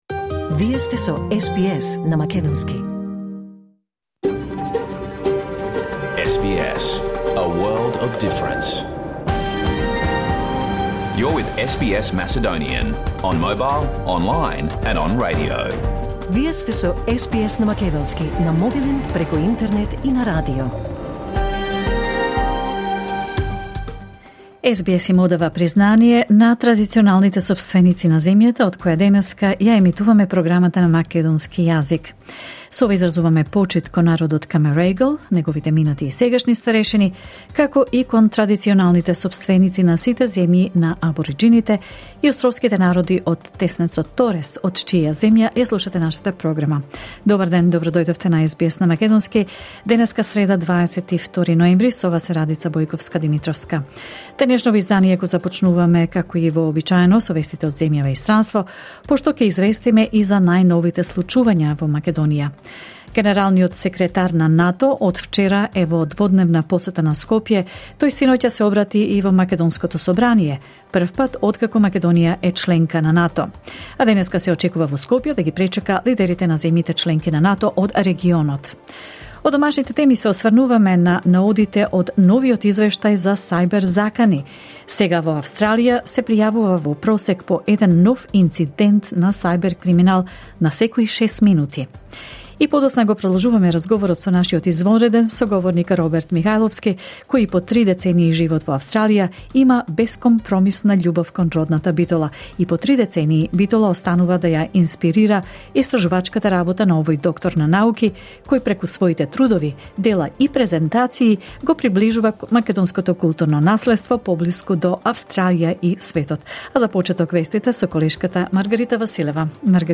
SBS Macedonian Program Live On Air 22 November 2023